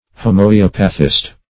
\Ho`m[oe]*op"a*thist\, n., Homoeopathy \Ho`m[oe]*op"a*thy\, n.